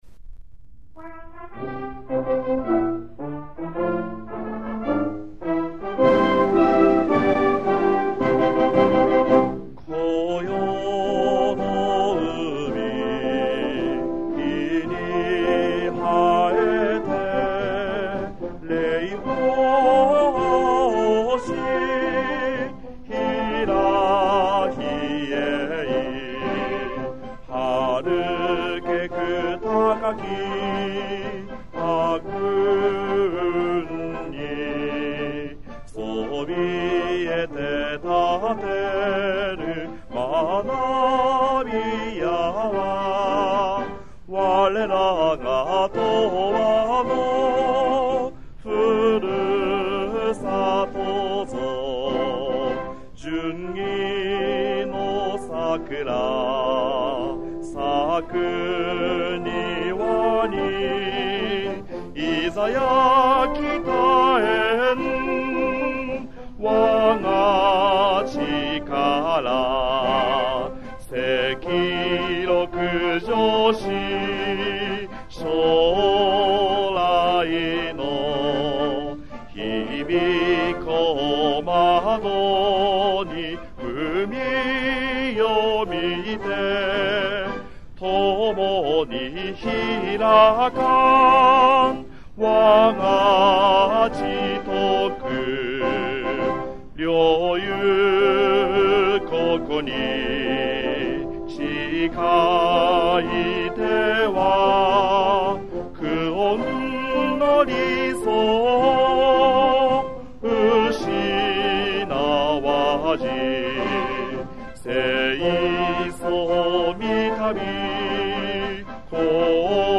校歌
♪メロディー再生
schoolsong.mp3